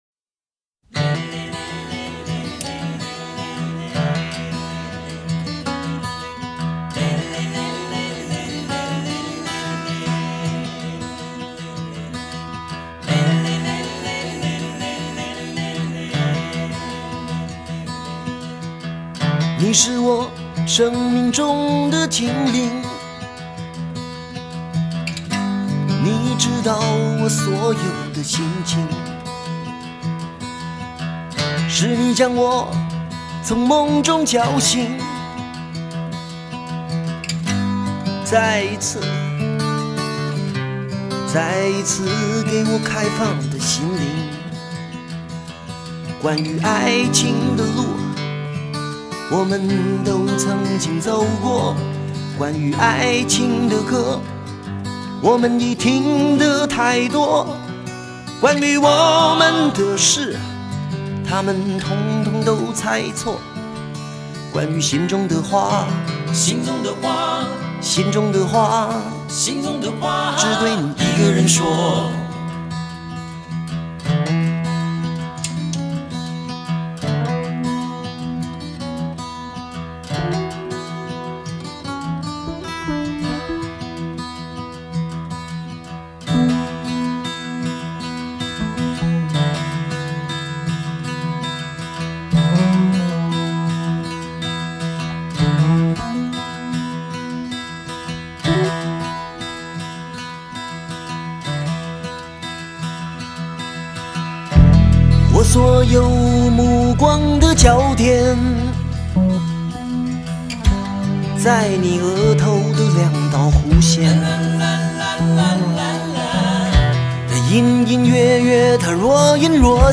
风格: 流行